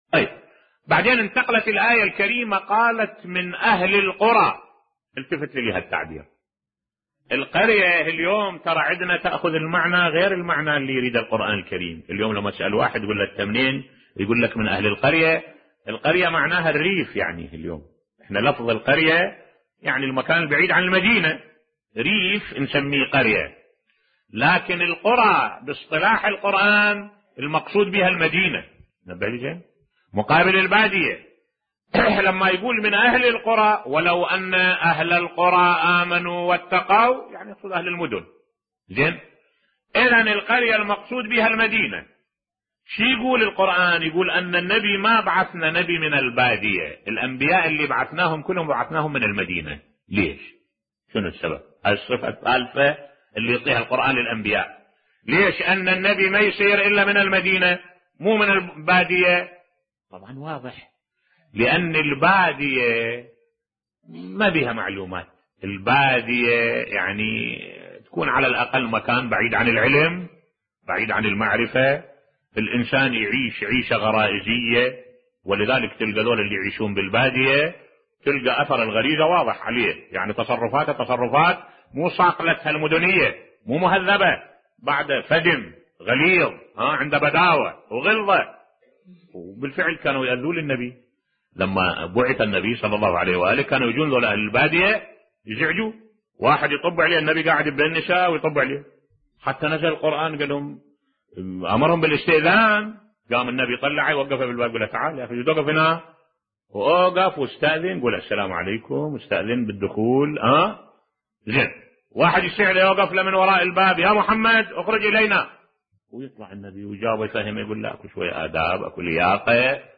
ملف صوتی كلام معبر في حق البداوة والأعراب بصوت الشيخ الدكتور أحمد الوائلي